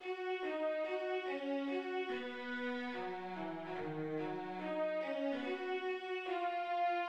The later section "On Flowering Meadows" also makes extensive use of orchestral pictorialism—the meadow is suggested by a gentle backdrop of high string chords, the marching theme is heard softly in the cellos, and isolated points of color (short notes in the winds, harp, and pizzicato in the violas, representing small Alpine flowers) dot the landscape.[4] In this section, a wavy motif in the strings appears and will feature more prominently at the summit as a majestic dotted rhythm.